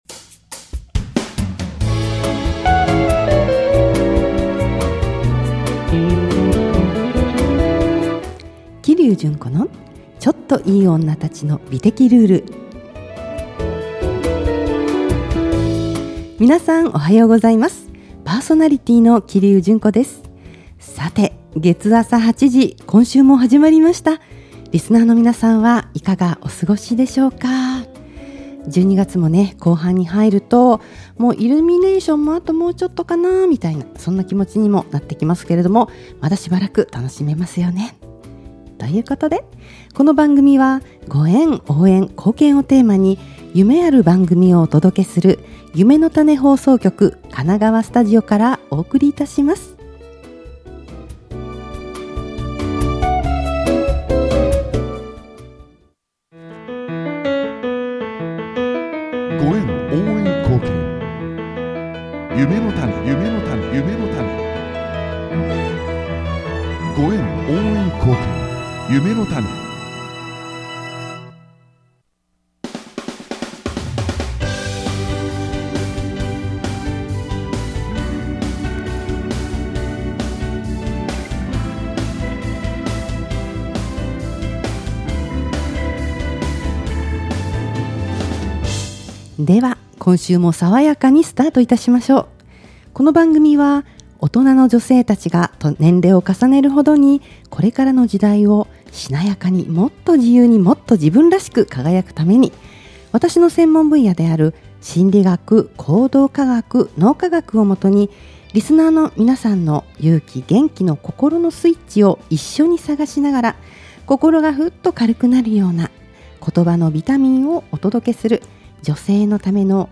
とても柔らかな声で、ハキハキ聴きやすいです！